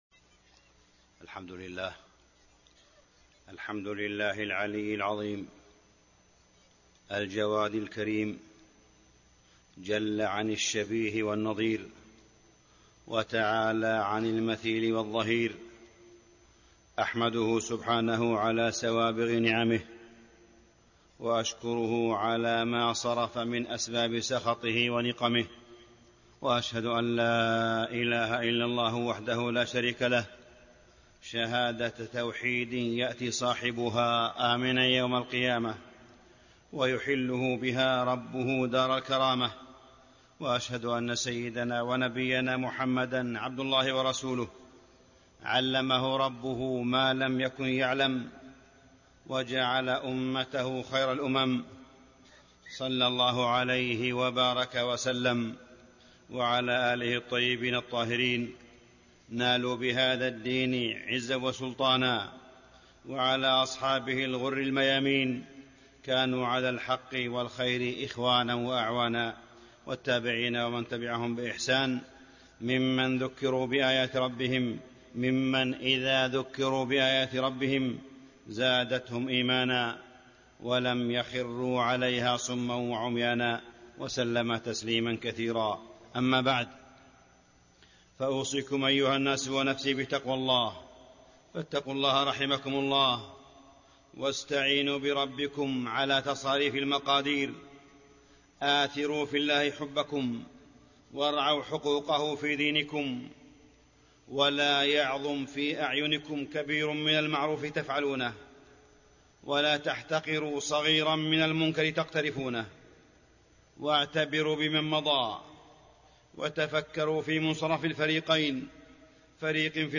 تاريخ النشر ٢٢ صفر ١٤٣٤ هـ المكان: المسجد الحرام الشيخ: معالي الشيخ أ.د. صالح بن عبدالله بن حميد معالي الشيخ أ.د. صالح بن عبدالله بن حميد الفساد آثاره وكيفية مكافحته